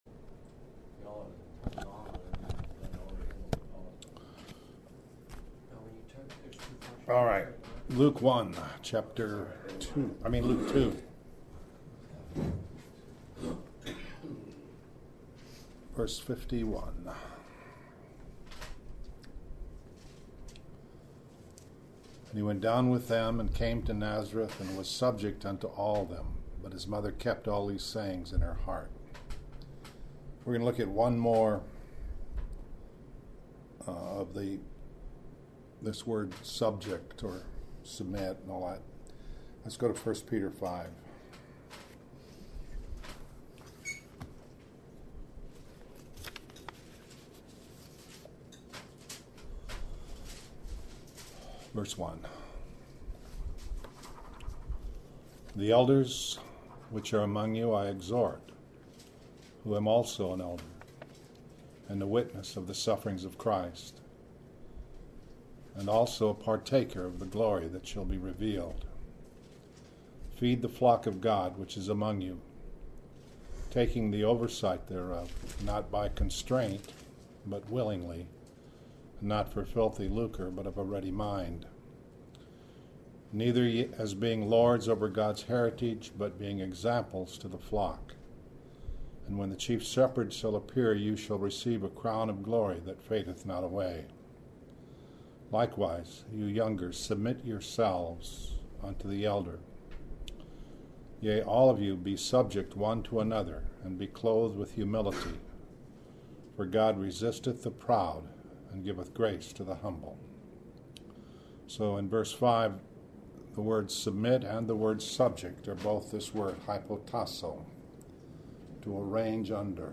Morning Bible Studies